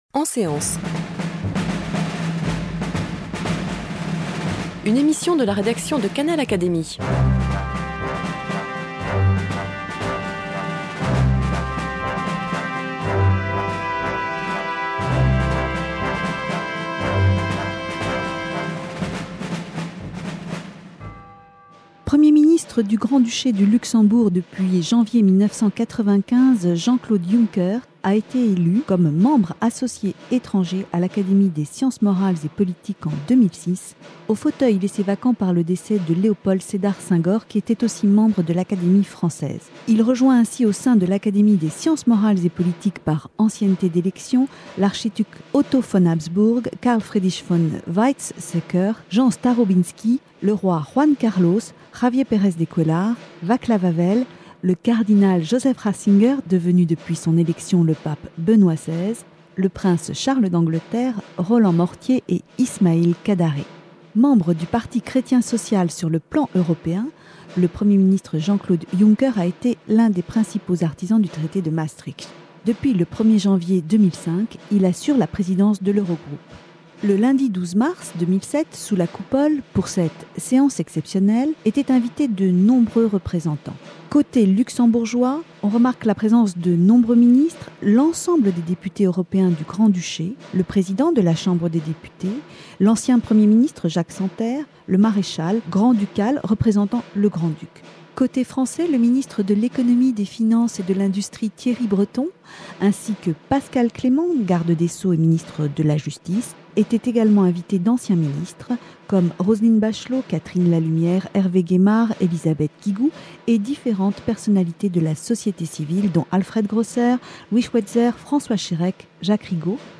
Réception de Jean-Claude Juncker sous la Coupole, le 12 mars 2007
Séance solennelle d’installation de Jean-Claude Juncker, membre associé étranger de l’Académie des sciences morales et politiques .Le Premier ministre du Grand Duché du Luxembourg a été élu au fauteuil de Léopold Sédar Senghor depuis le 9 octobre 2006. Ecoutez la retransmission de cette séance.